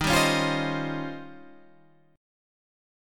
D#13 chord